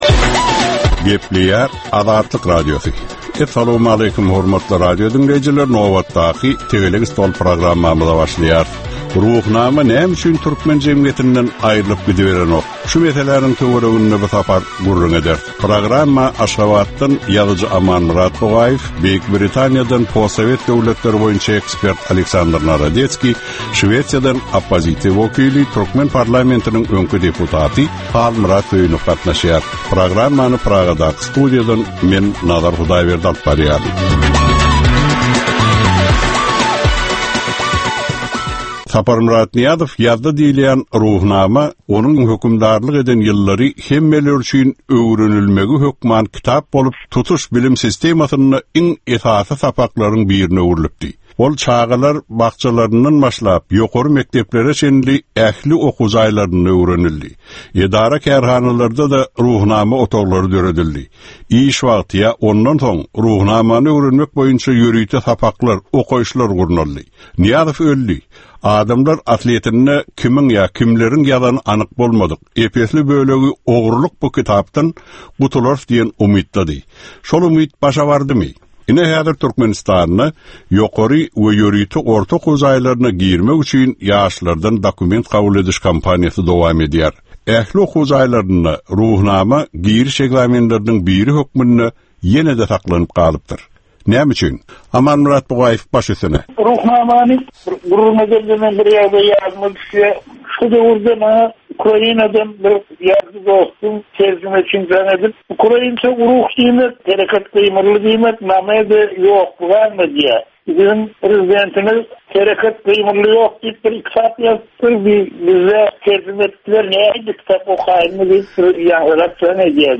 Jemgyýetçilik durmusynda bolan ýa-da bolup duran sonky möhum wakalara ýa-da problemalara bagyslanylyp taýyarlanylýan ýörite Tegelek stol diskussiýasy. 30 minutlyk bu gepleshikde syýasatçylar, analitikler we synçylar anyk meseleler boýunça öz garaýyslaryny we tekliplerini orta atýarlar.